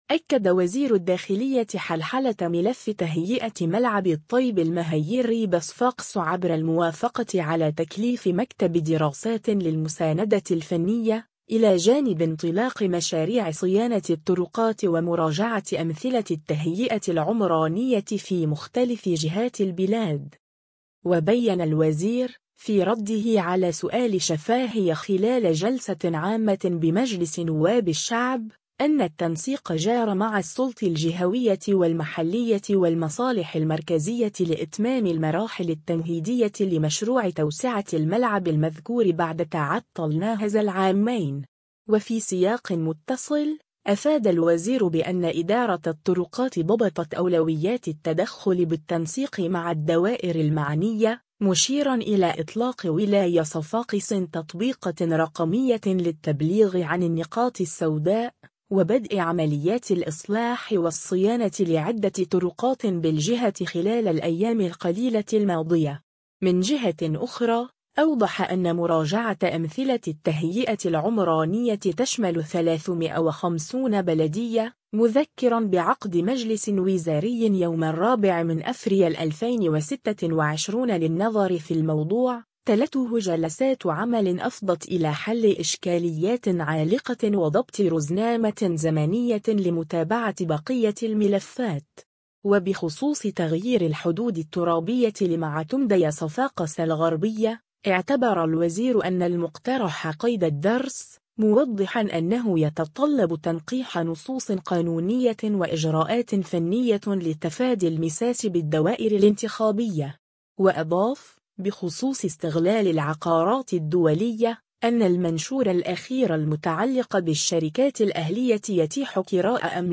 و بيّن الوزير، في رده على سؤال شفاهي خلال جلسة عامة بمجلس نواب الشعب، أن التنسيق جار مع السلط الجهوية والمحلية والمصالح المركزية لإتمام المراحل التمهيدية لمشروع توسعة الملعب المذكور بعد تعطل ناهز العامين.